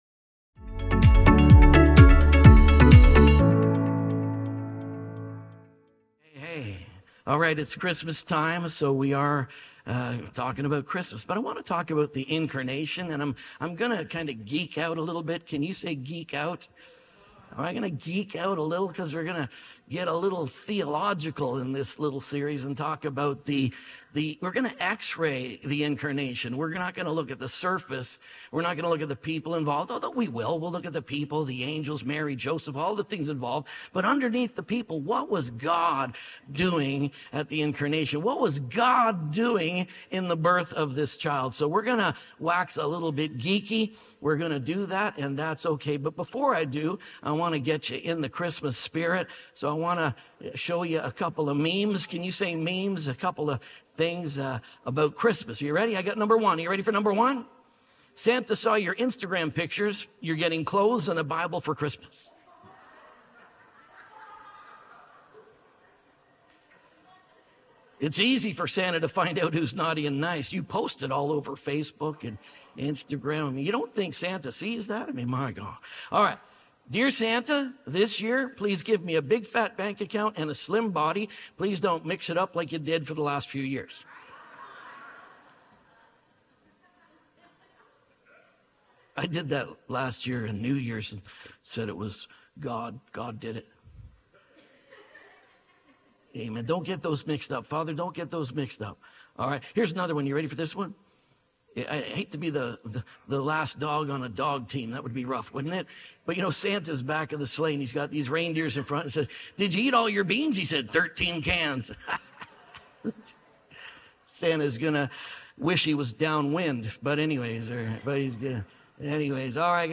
WORD BECOMES FLESH | INCARNATION - GOD BECOMES FLESH |  | SERMON ONLY 2.mp3